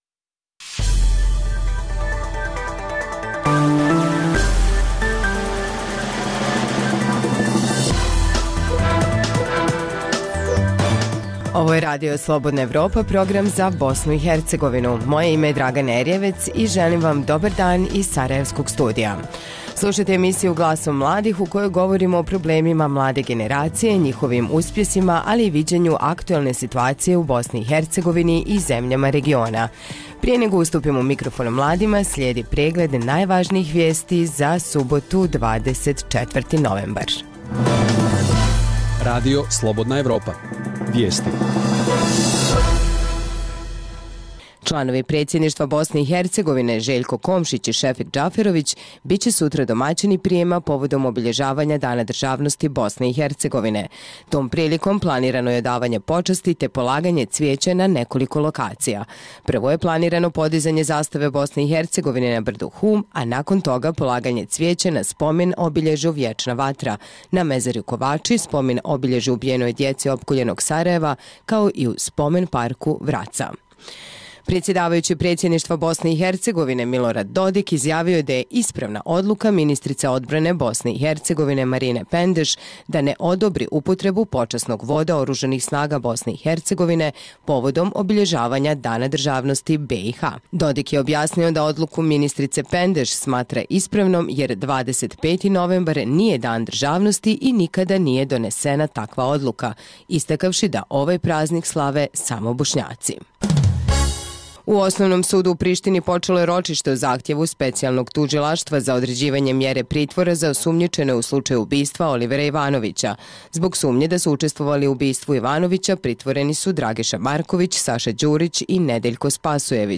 U ovosedmičnoj emisiji pitali smo mlade iz BiH, Srbije i Crne Gore da li slušaju i koliko često odlaze na koncerte klasične muzike.